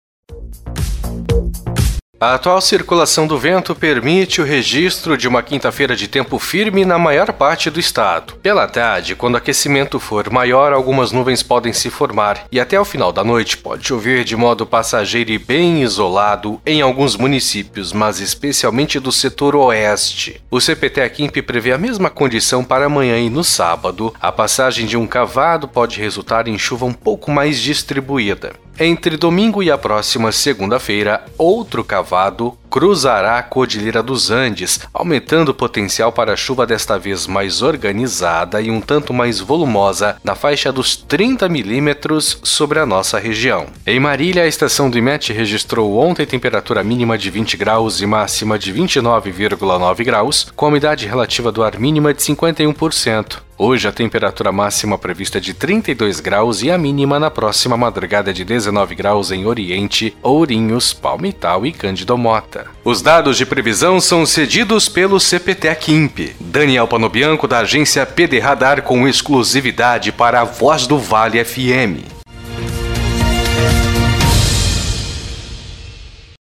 Previsão do Tempo para esta quinta – Ouça Agência PDRadar